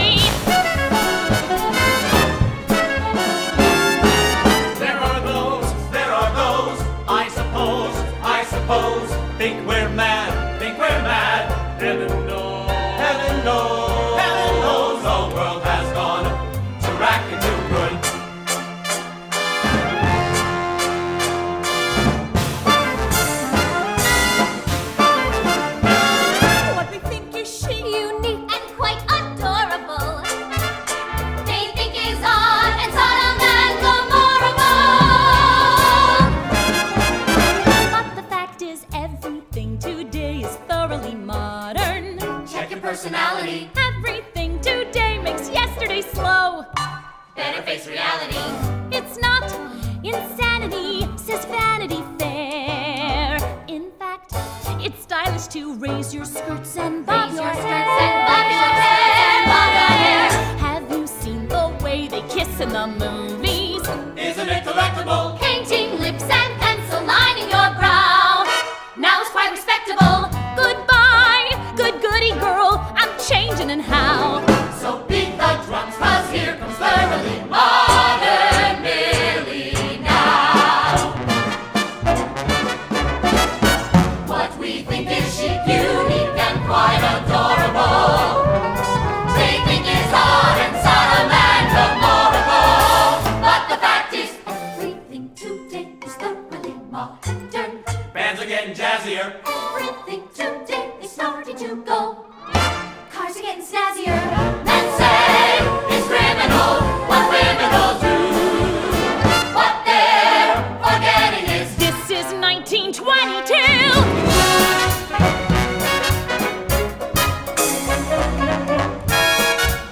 1967 Genre: Musical   Artist